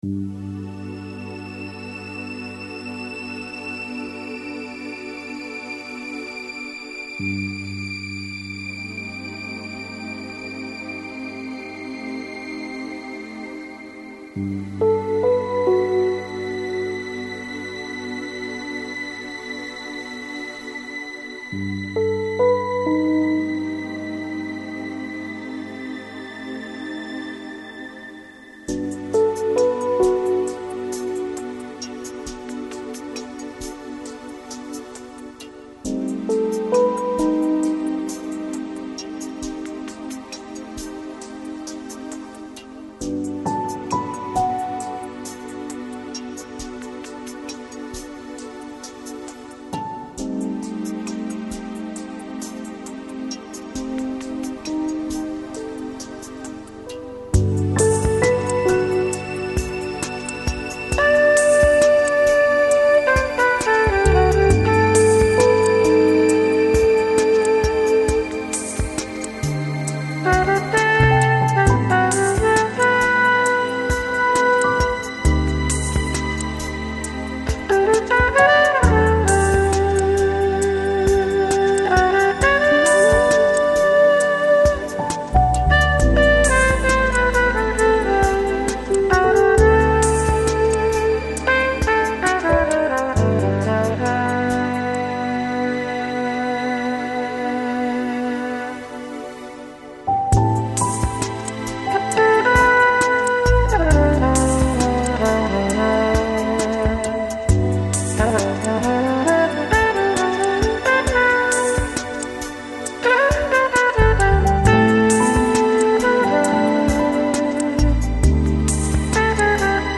Жанр: Downtempo, Chill Out, Lounge